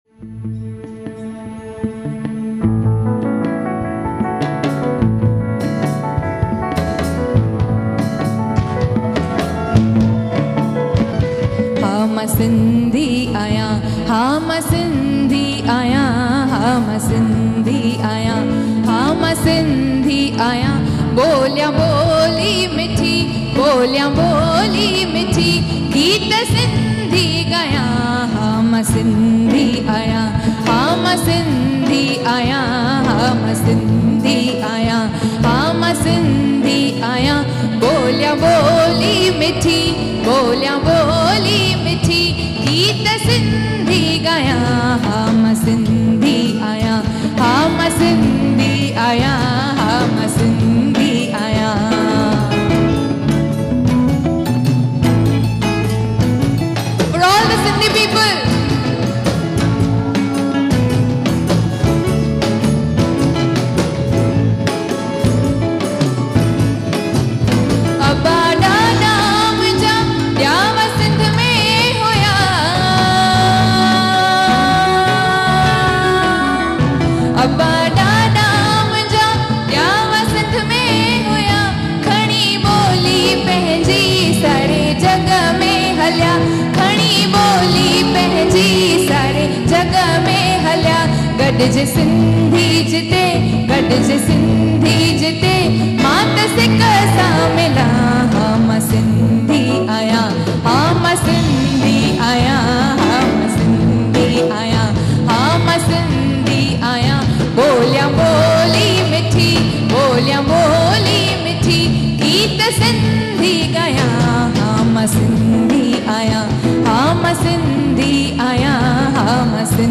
.New Generation Rock style Sindhi Songs.